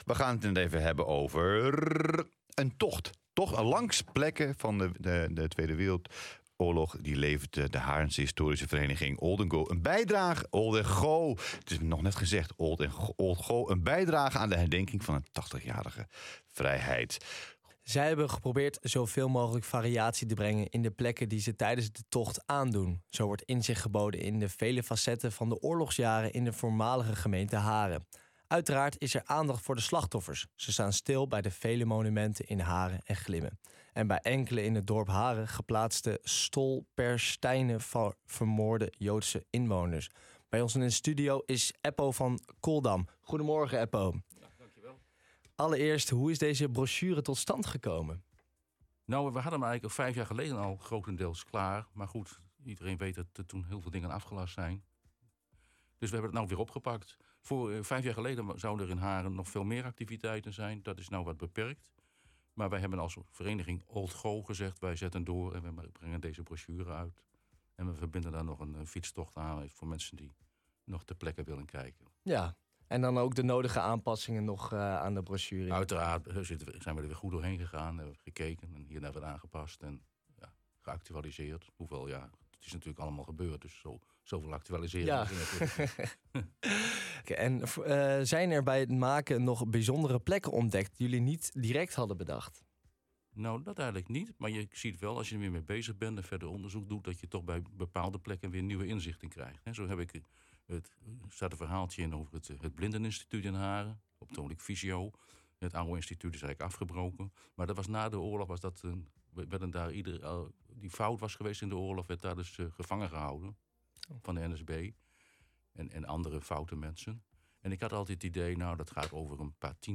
Elke dag is er een ander interview te beluisteren in de OOG ochtendshow, een nieuwsprogramma op OOG Radio, elke werkdag van 7.00 tot 9.00 uur. 80 jaar vrijheid Glimmen Haren old go Oog Ochtendshow
interview-29-4.mp3